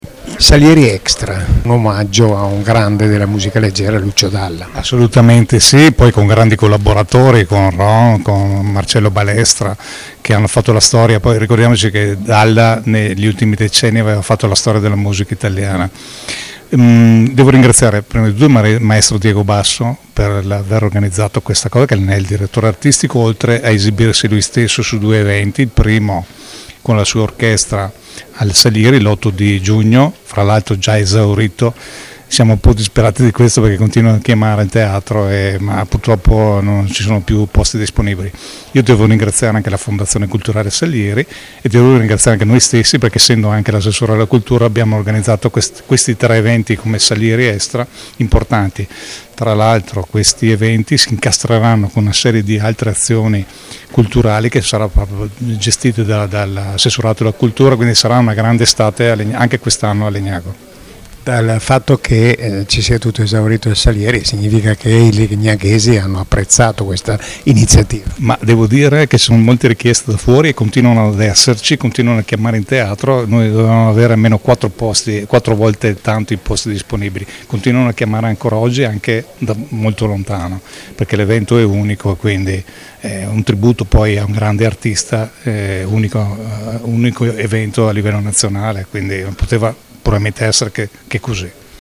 Ecco le dichiarazioni raccolte nella giornata di presentazione
Graziano Lorenzetti, sindaco di Legnago
Graziano-Lorenzetti-sindaco-di-Legnago.mp3